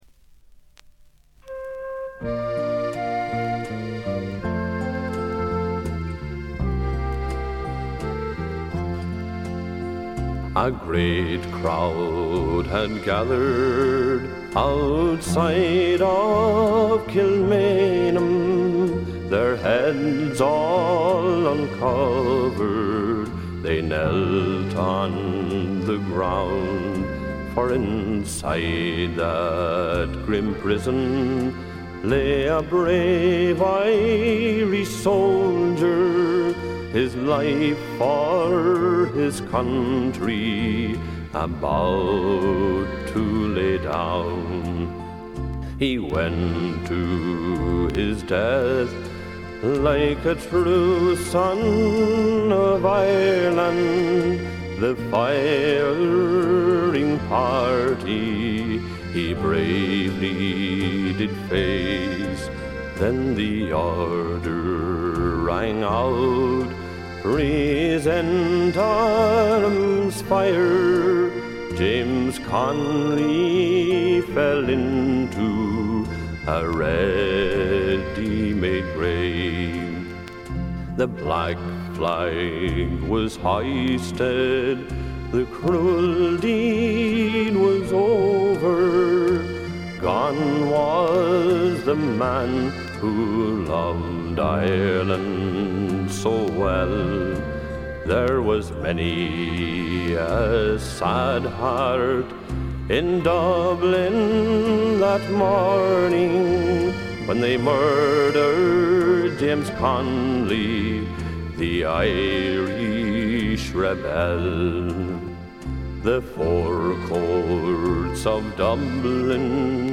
バックグラウンドノイズ、チリプチ、プツ音等多め大きめ。ところどころで周回ノイズもあり。
あとは鼓笛隊的な音が好きなマニアかな？？
試聴曲は現品からの取り込み音源です。
Recorded At - Trend International